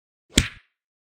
Download Punch sound effect for free.
Punch